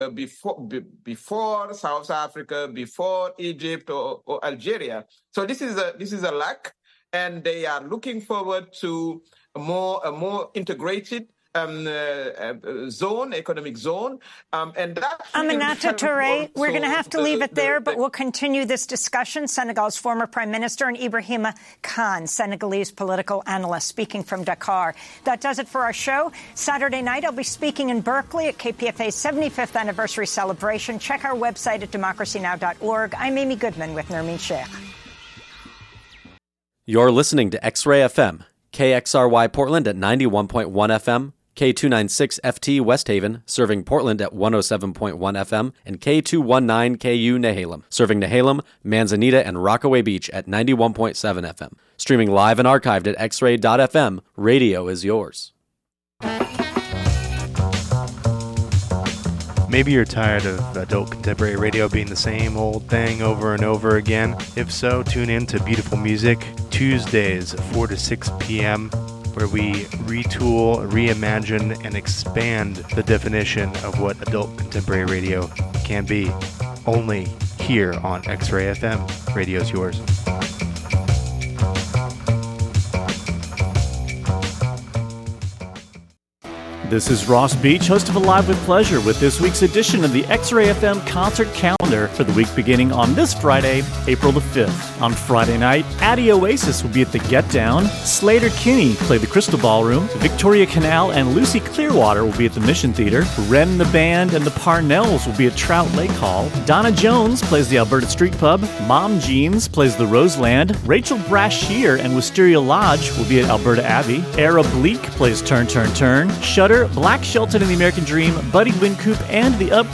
Our interview with the Vancouver Volcanoes, the city’s professional basketball team.